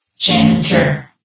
CitadelStationBot df15bbe0f0 [MIRROR] New & Fixed AI VOX Sound Files ( #6003 ) ...
janitor.ogg